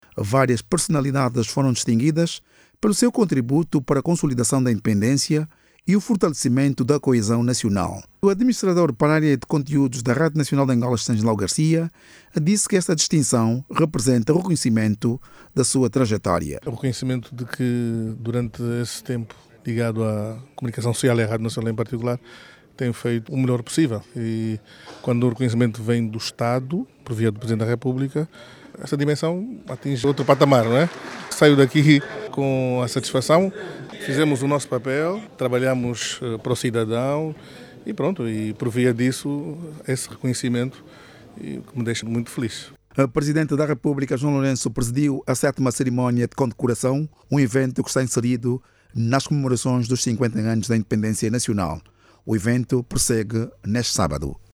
A sétima cerimónia de condecoração em alusão as comemorações dos 50 anos de Independência Nacional prossegue hoje, sábado, 25, numa das unidades hoteleiras da capital Luanda . O evento, que teve início ontem, sexta-feira(24), distingue personalidades e instituições que se destacaram ao longo das cinco décadas de soberania nacional. Saiba mais dados no áudio abaixo com o repórter